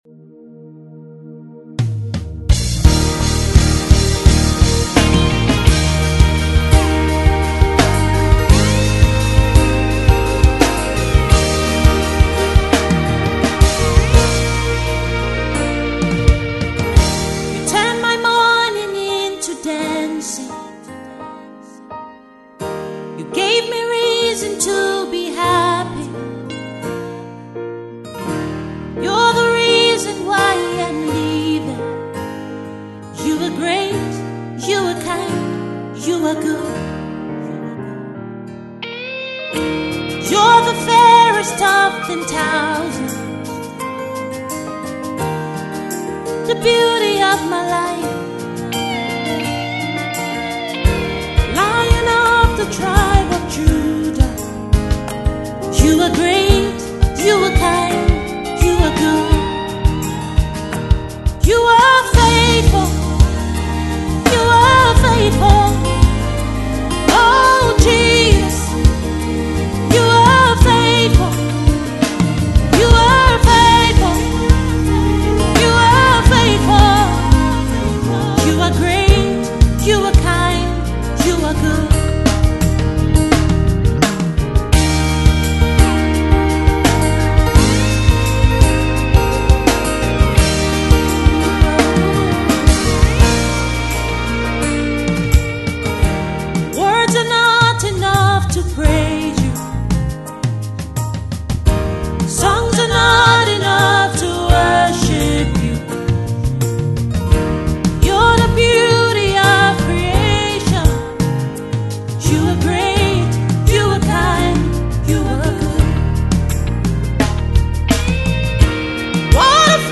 is a worship song born out of a grateful heart